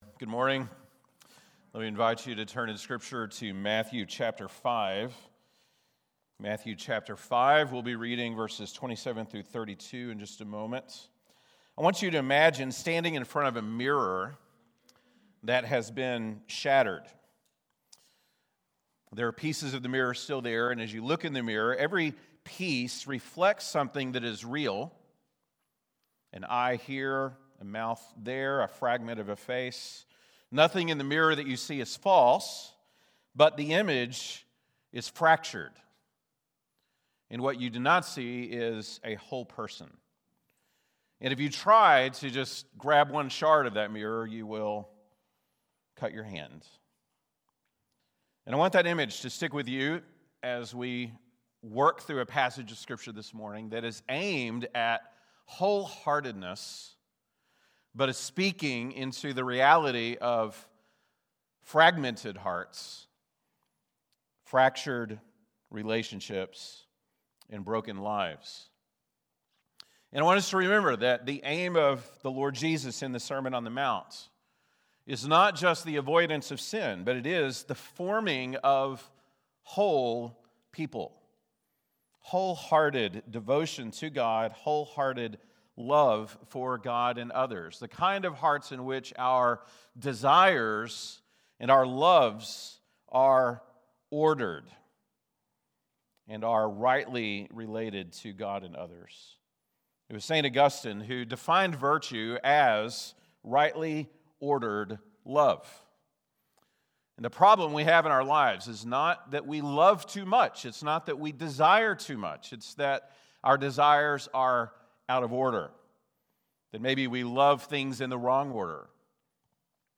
January 25, 2026 (Sunday Morning)